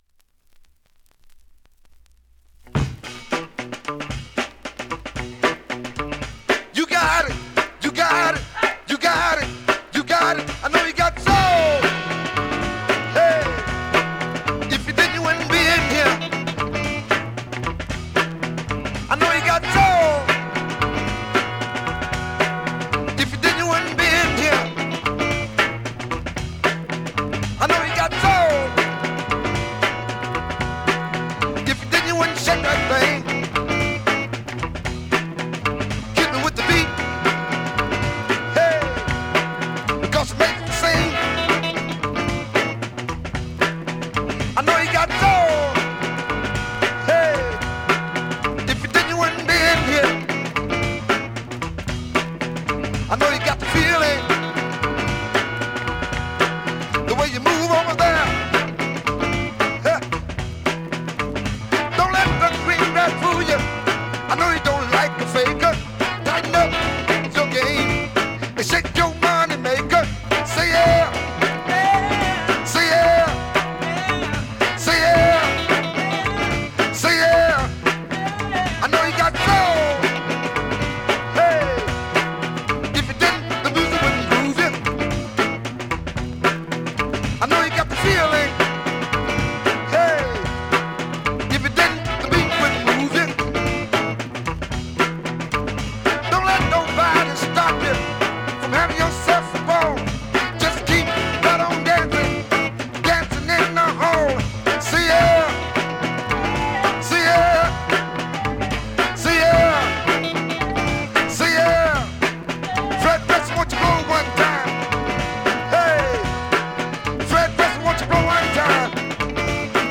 現物の試聴（両面すべて録音時間６分１４秒）できます。